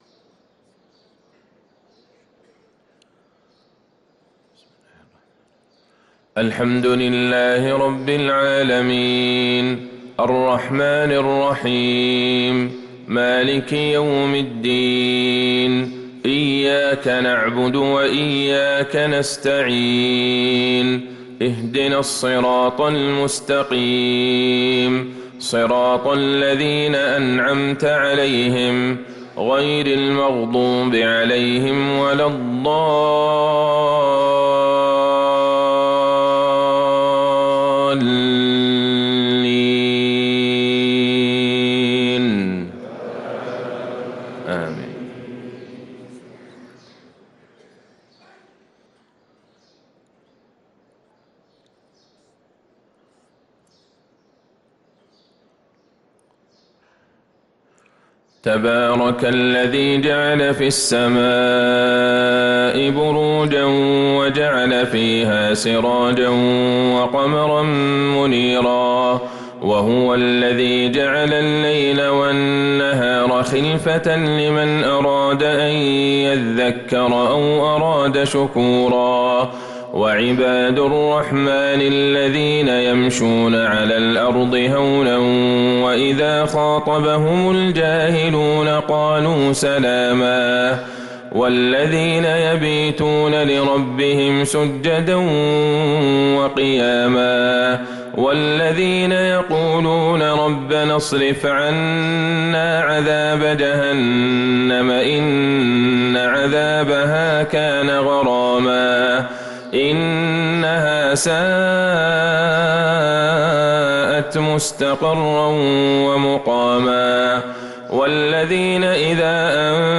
تِلَاوَات الْحَرَمَيْن .